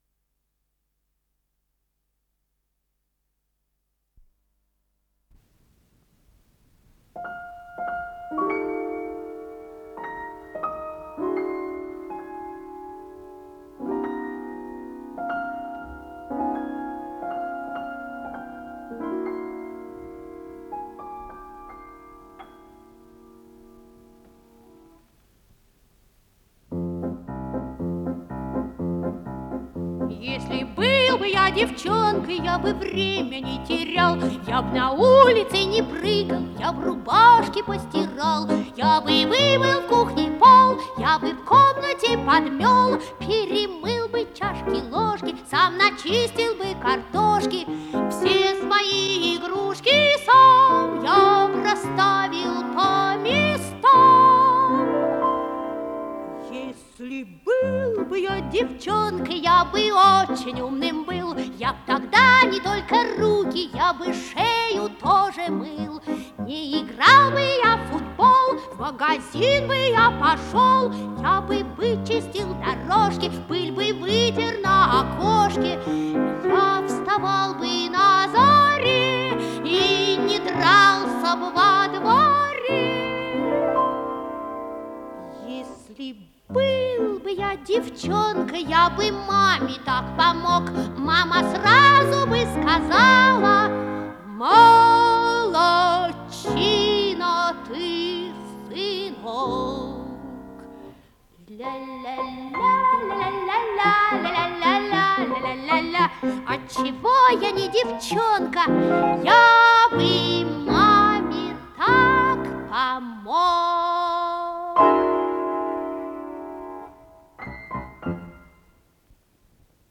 пение
фортепиано